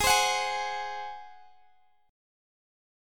Listen to G#7 strummed